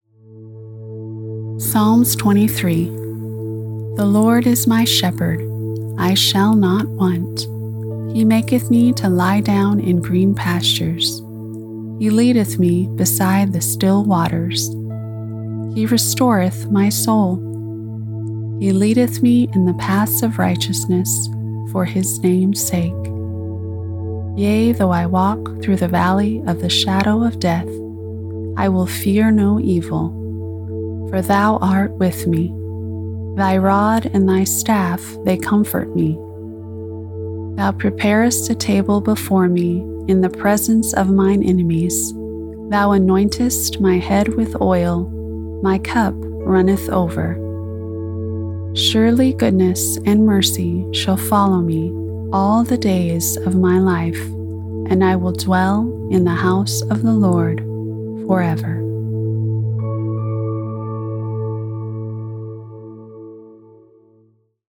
Devotional Scripture Reading Psalms 23
English - Midwestern U.S. English
Operate a broadcast-quality home studio featuring a Shure SM7B microphone and Universal Audio Apollo Twin interface, delivering clean WAV audio with quick turnaround and professional editing.
My voice has a calm, reassuring quality that helps listeners feel both informed and encouraged.